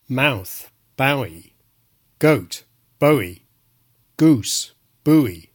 can sound rather like Southern England’s GOAT and GOOSE respectively:
bowie_ssb.mp3